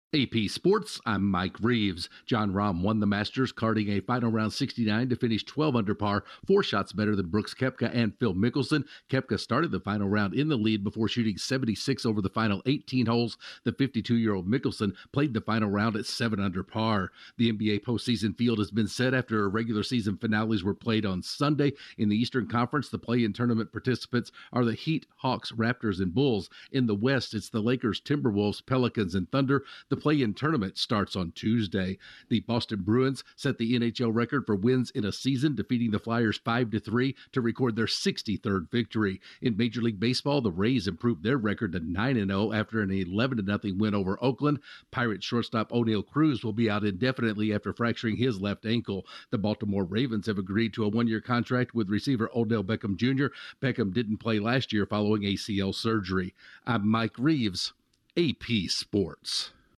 AP Sports SummaryBrief at 11:17 p.m. EDT